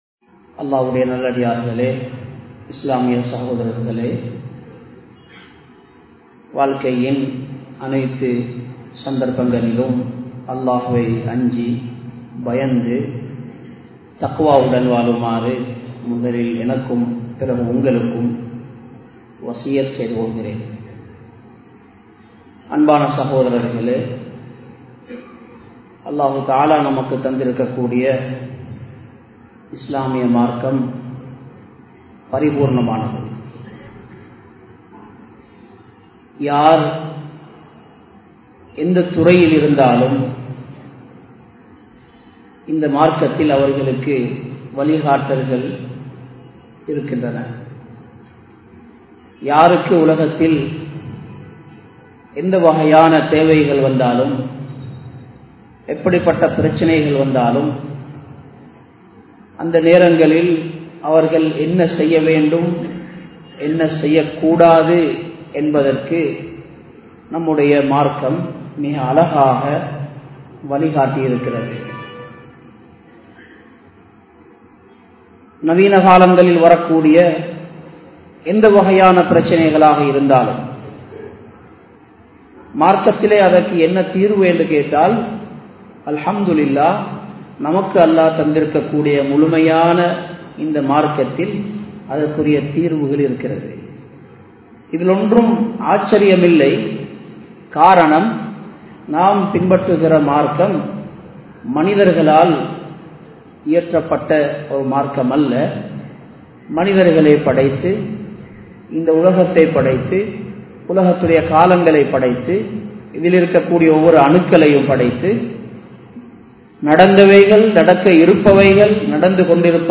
Payanam Sellum Mun (பயணம் செல்லும் முன்) | Audio Bayans | All Ceylon Muslim Youth Community | Addalaichenai